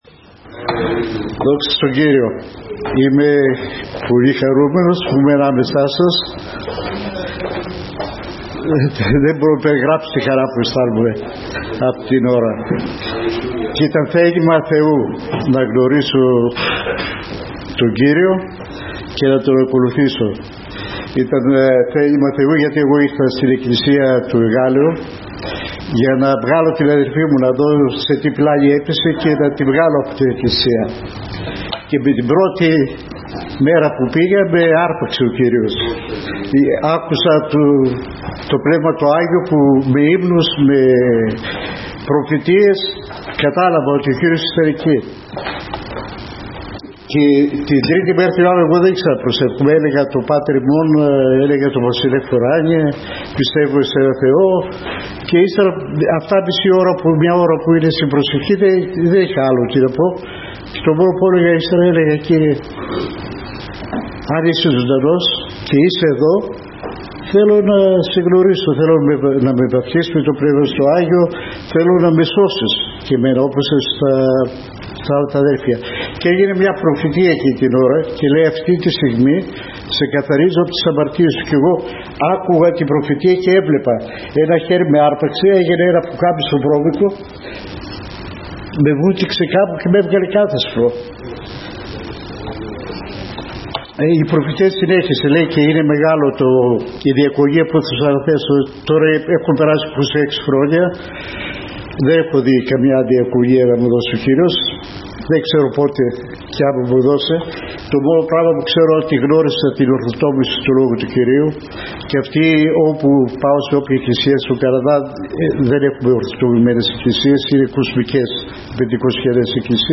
2019-08-01 ΟΜΟΛΟΓΙΑ ΚΑΙ ΚΗΡΥΓΜΑ